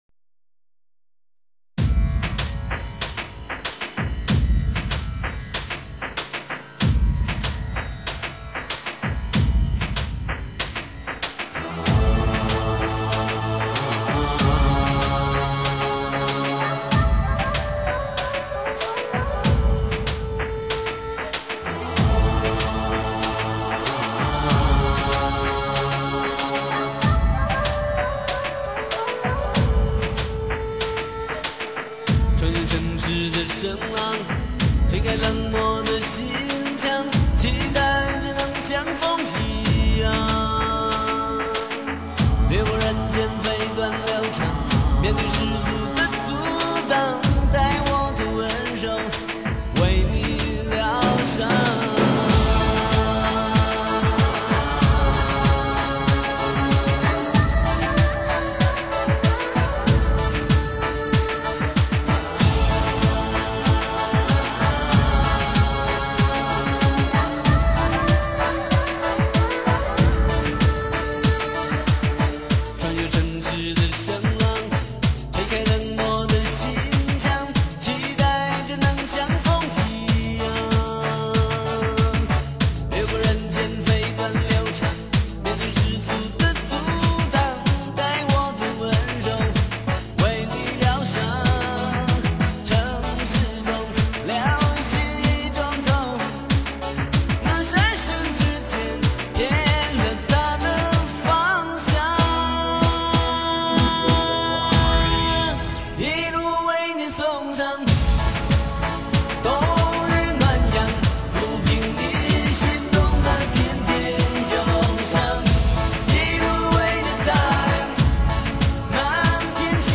曲风落寞苍凉，任性、痛快